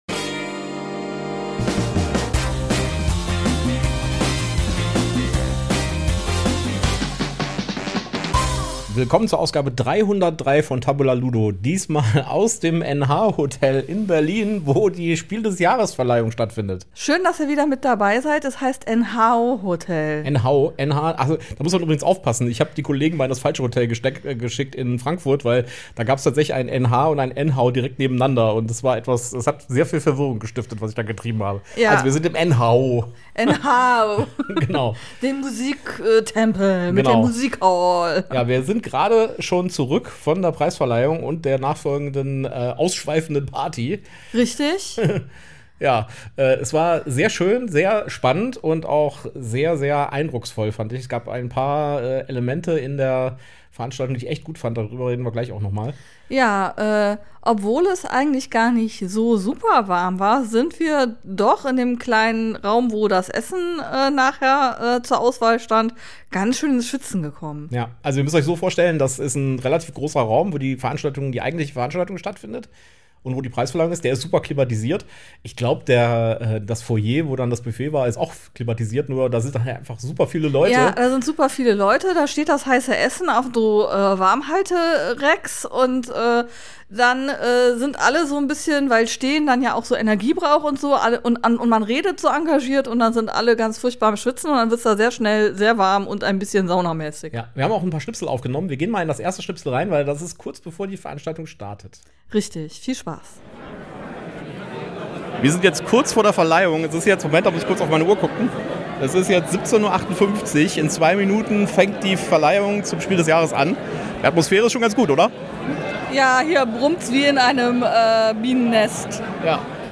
Wir waren vor Ort und haben für euch mit den Gewinnern gesprochen und hören die emotionalsten Momente. Wir nehmen euch mit auf den Event und fangen die einzigartige Atmosphäre für euch ein.